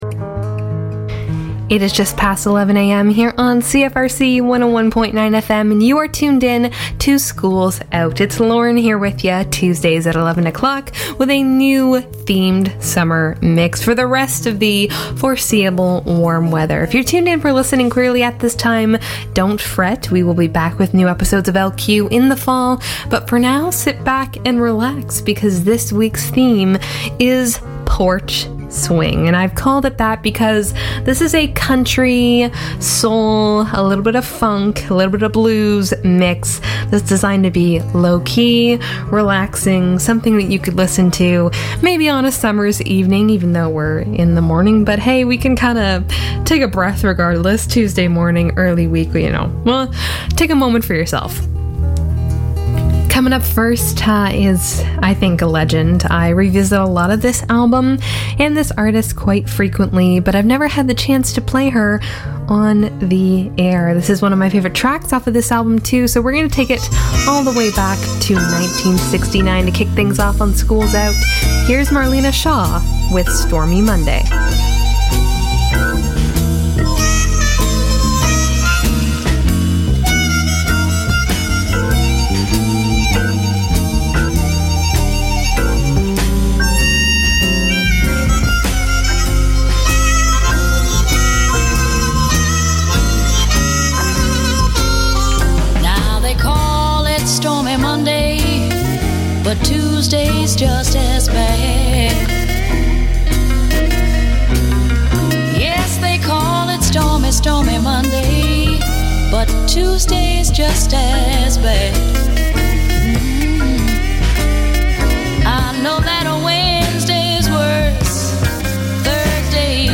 Still multi-genre, still your new favourite music, but with a fresh summer theme for a new experience every week.